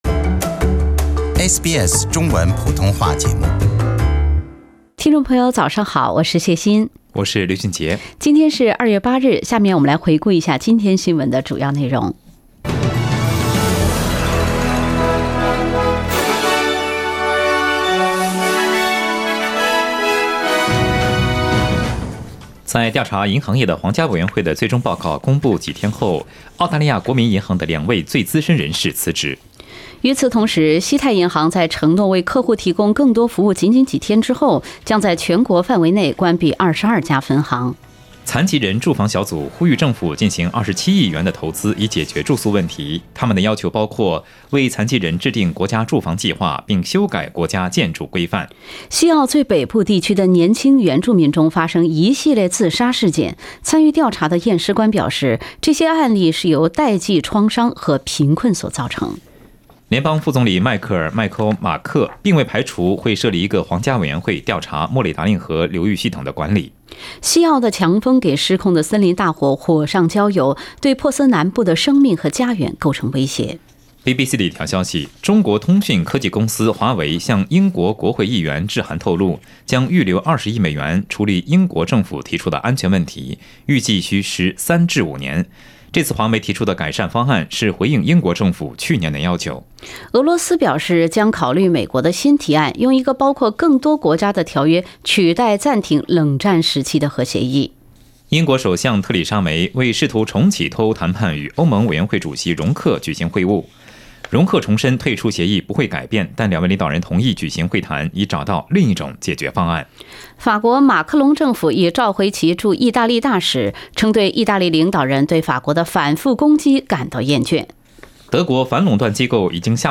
SBS早新聞 （2月8日）